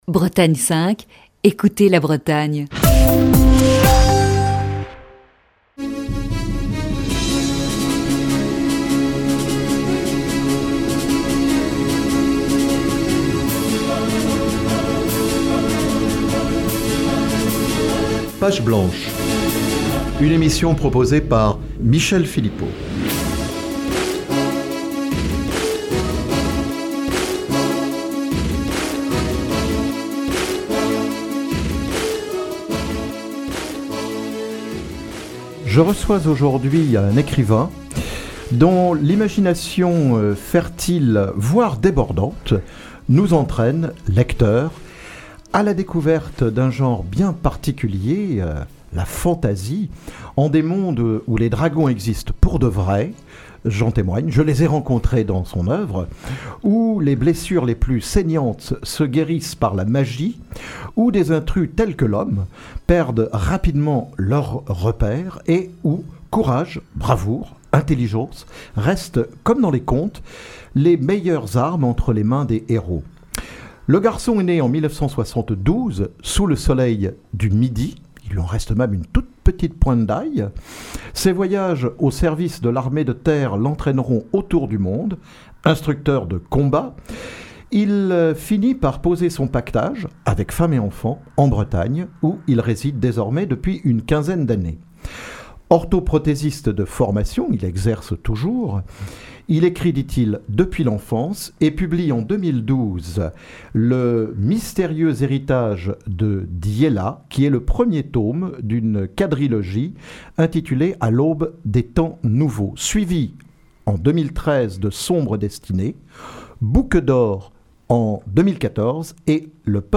Direct sur Page Blanche Radio Bretagne 5 le 08 décembre 2016